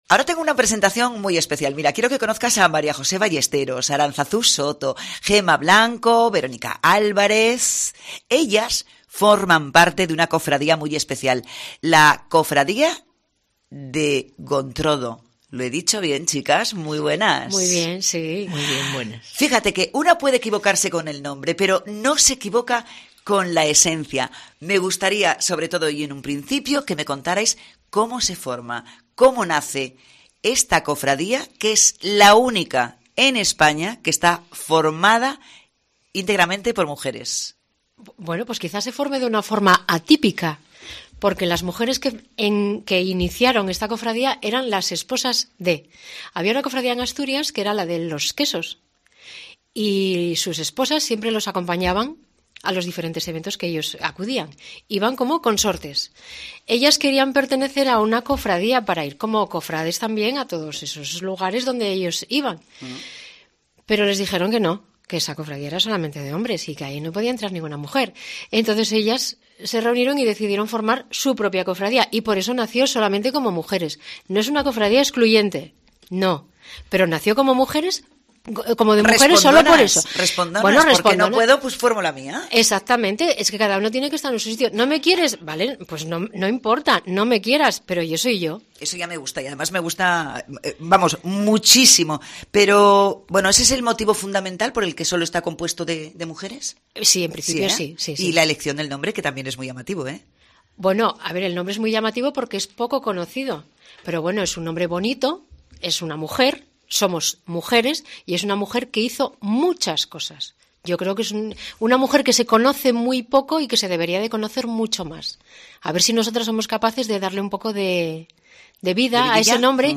Entrevista con la cofradía de Doña Gontroda